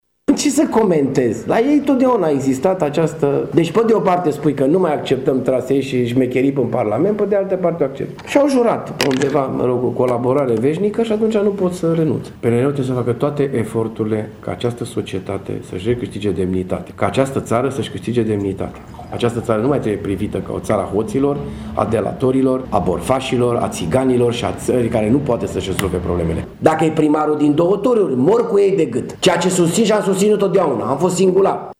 Primarul Tg.Mureșului, Dorin Florea, crede că în cazul primarilor e nevoie de două tururi de scrutin: